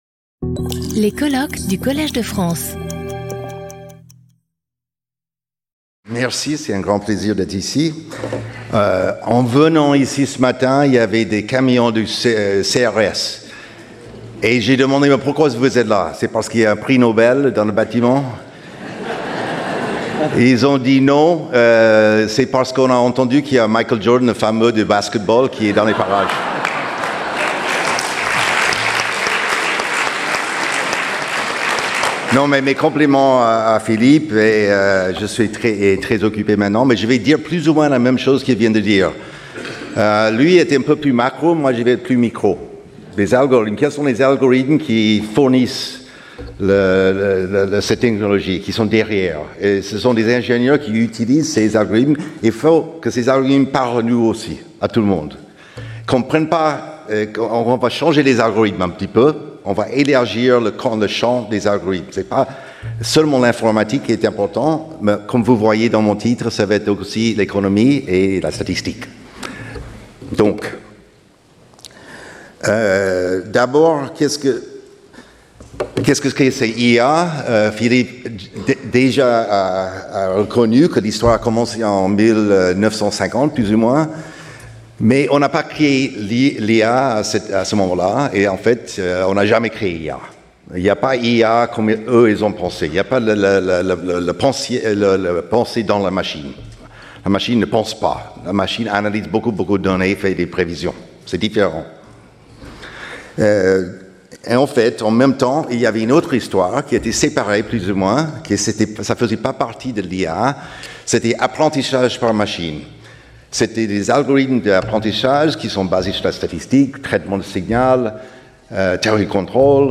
Speaker(s) Michael I. Jordan